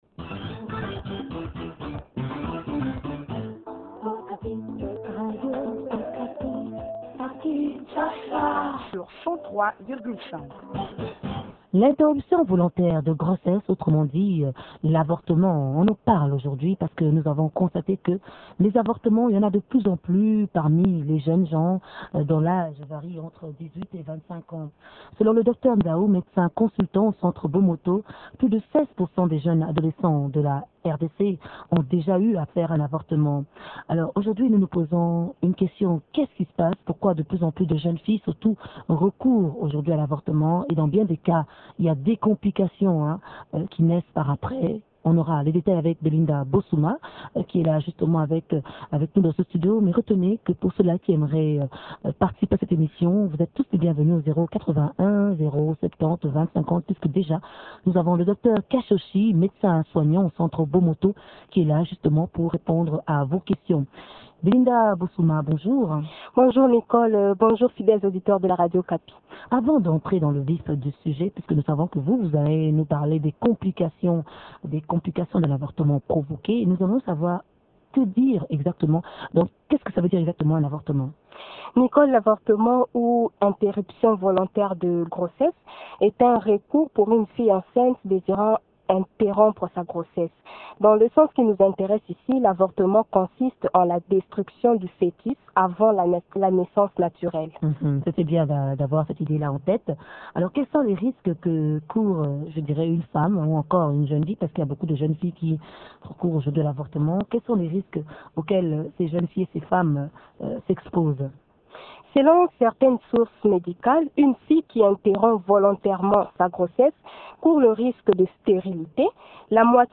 Cependant, l?avortement provoqué entraîne plusieurs conséquences néfastes qui peuvent ne pas être immédiates. Invité à Okapi action